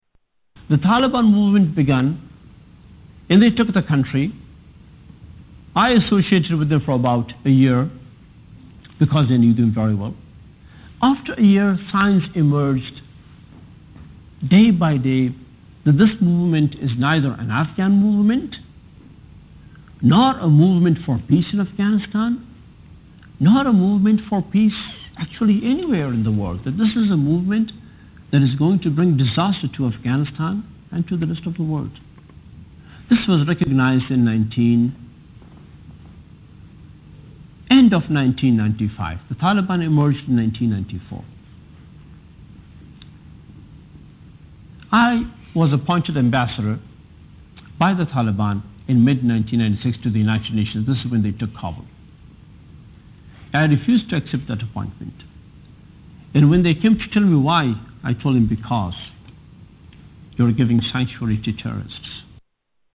Tags: Political Hamid Karzai audio Interviews President Afghanistan Taliban